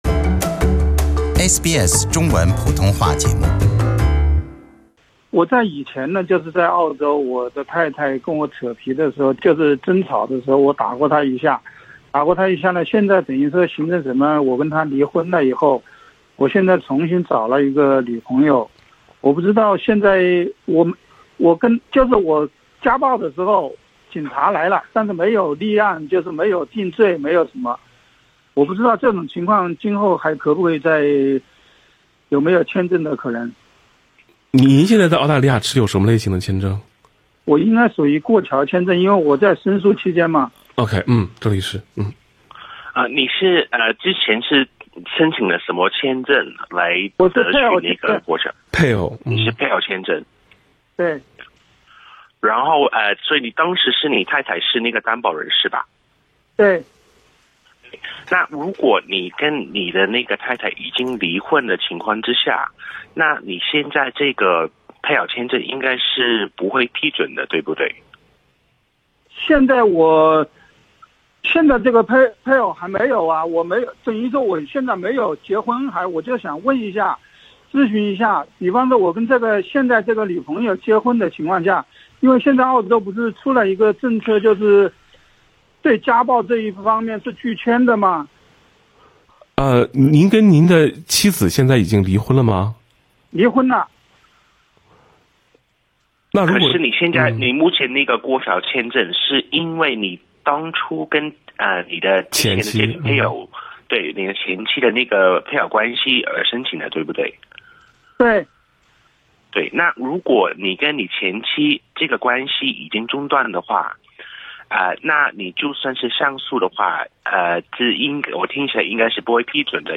这是某期《现场说法》节目中一位参与听众讲述的发生在他自己身上的真实故事。